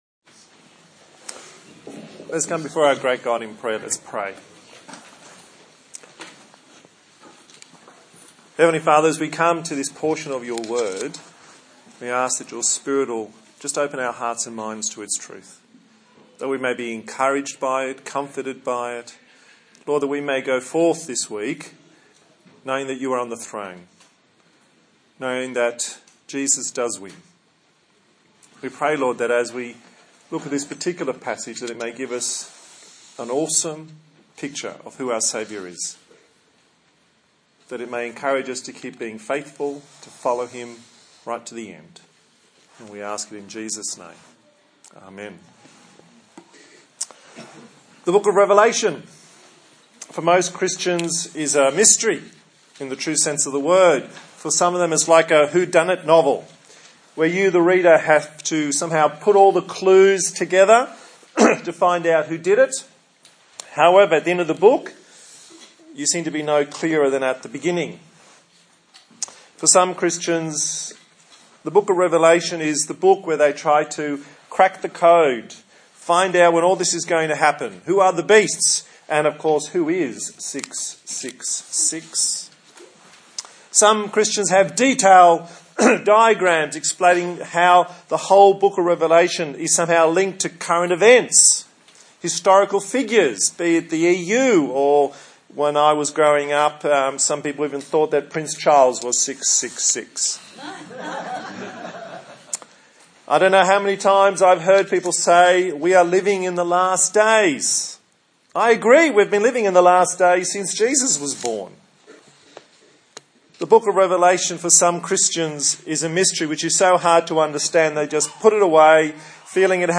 Service Type: Sunday Morning A sermon in the series on the book of Revelation